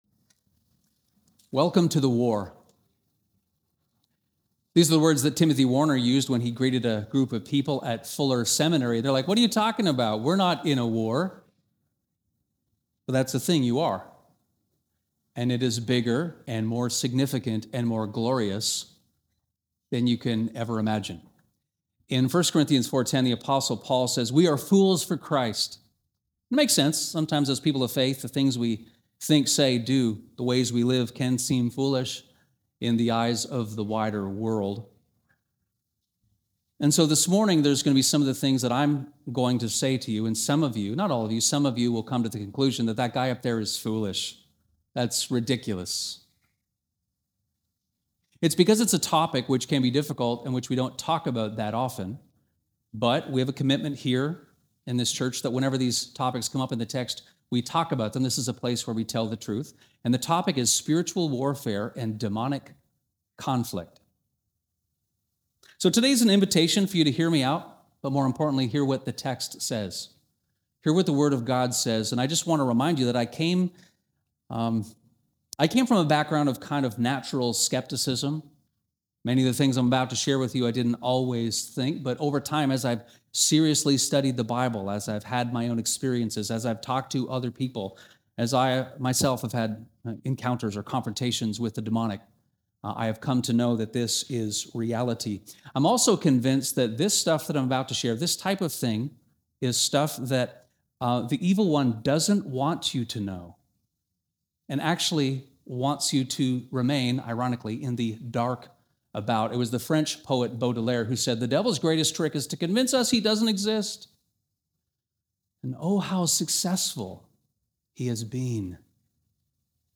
This sermon explains Daniel 10 and the mysterious battle between archangels and a demon-prince of Persia.